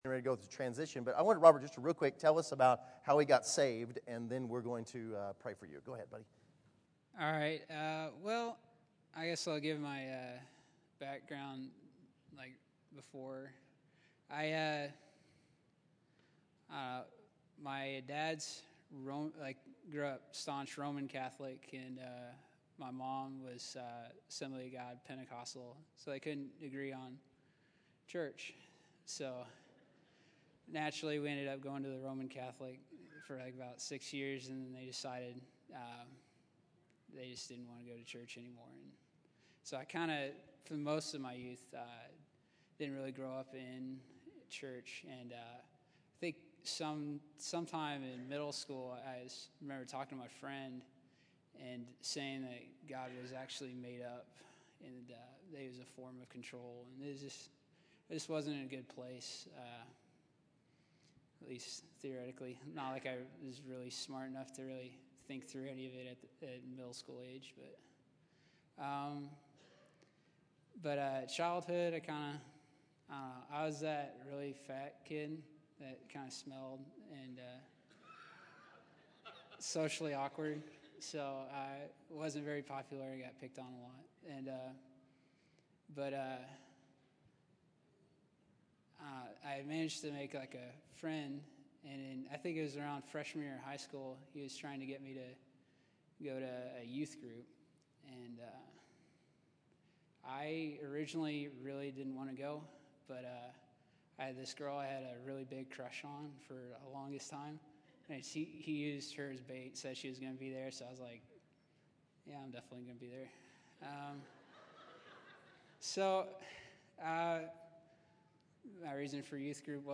January 12, 2014      Category: Testimonies      |      Location: El Dorado